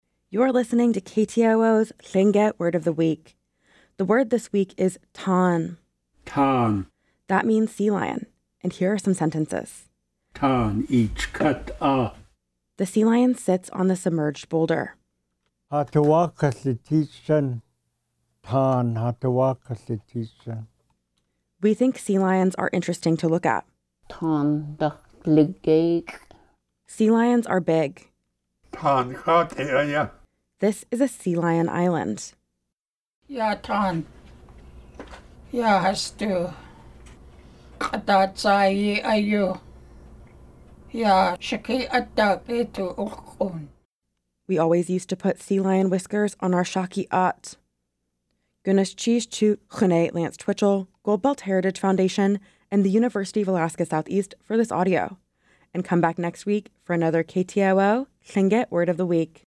Each week, we feature a Lingít word voiced by master speakers.